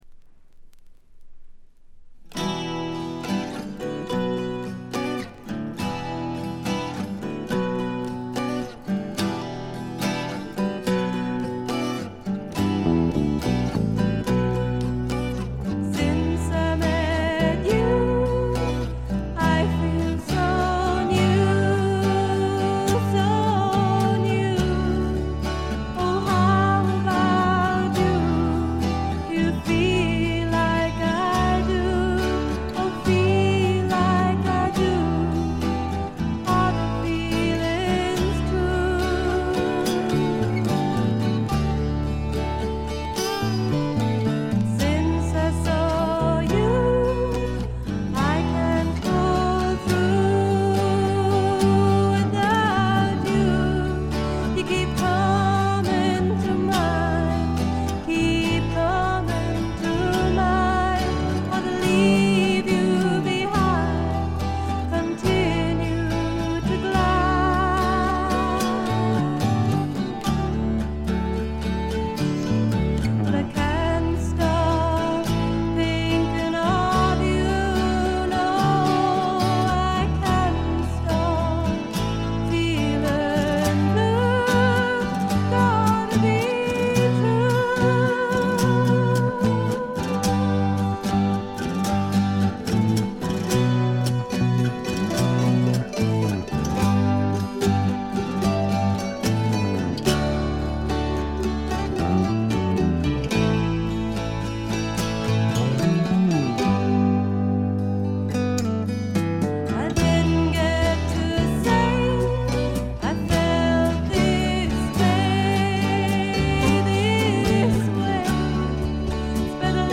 ほとんどノイズ感無し。
ひとことで言って上品で風格のあるフォーク･ロックです。
試聴曲は現品からの取り込み音源です。
Recorded At Marquee Studios